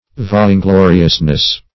-- Vain`glo"ri*ous*ness , n. [1913 Webster]